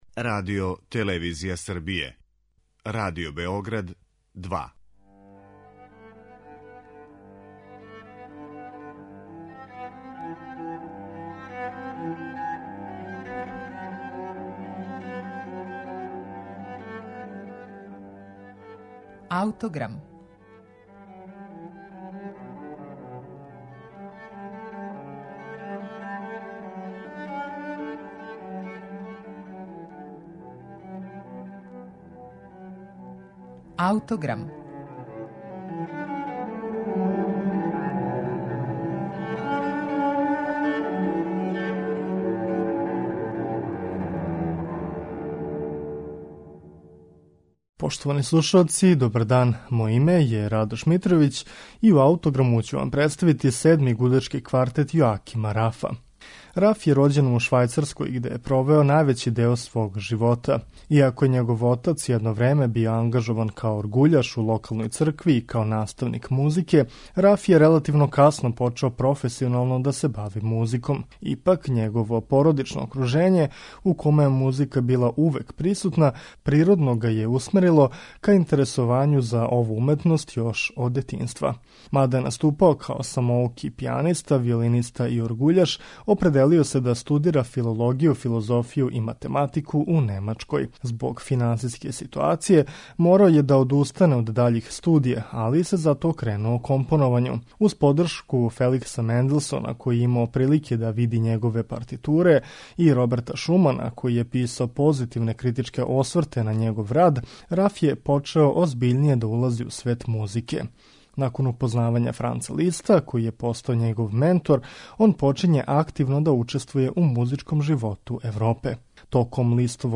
Седми гудачки квартет Јозефа Јоакима Рафа, слушаћете у извођењу Миланског квартетa.